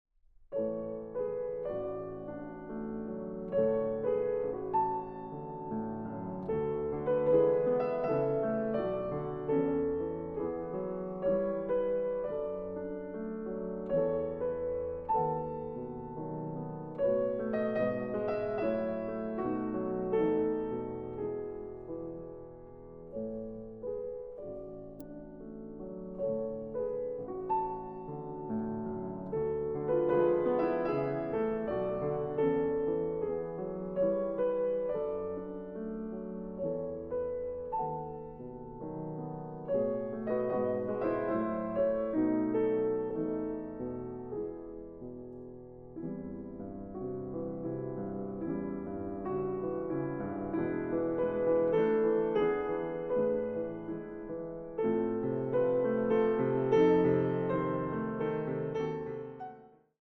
Piano
Intermezzo. Andante teneramente